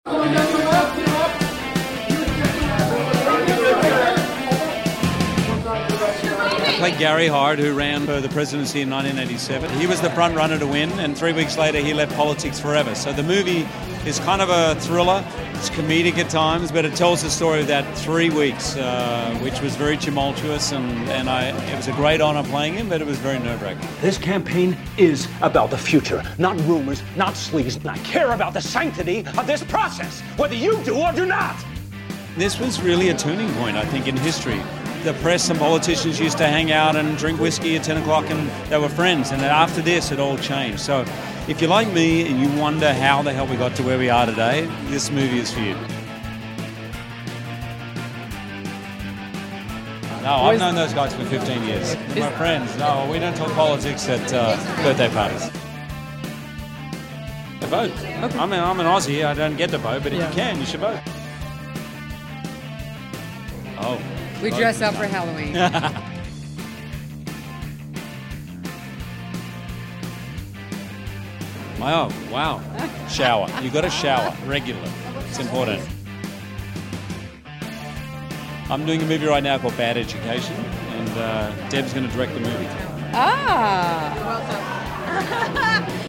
The Front Runner star, Hugh Jackman, and his wife Deborra-lee Furness came out for the political biopic’s premiere at the MoMA in New York City. We grilled the Aussie actor on everything from playing real life 1988 presidential hopeful, Gary Hart, to what he’s up to next, even about his 50th birthday party attendees, husband and wife Jared Kushner and Ivanka Trump.